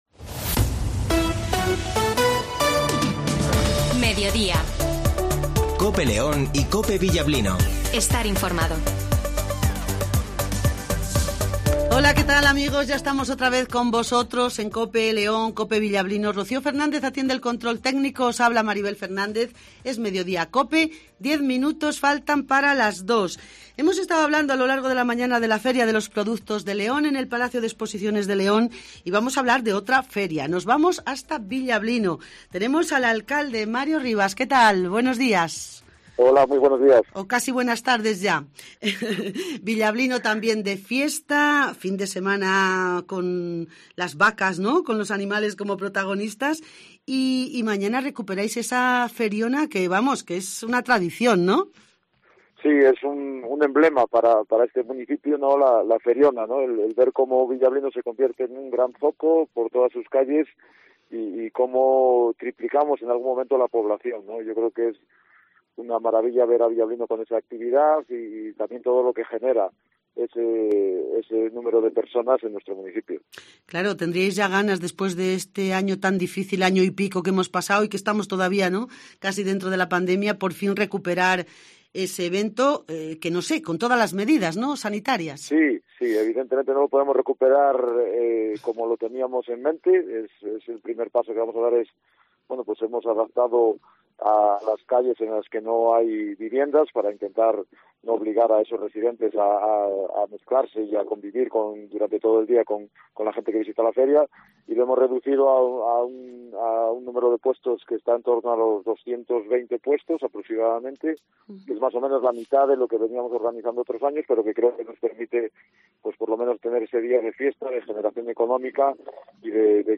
El alcalde de Villablino Mario Rivas nos cuenta como se celebra la " Feriona "
- Mario Rivas ( Alcalde de " Villablino " )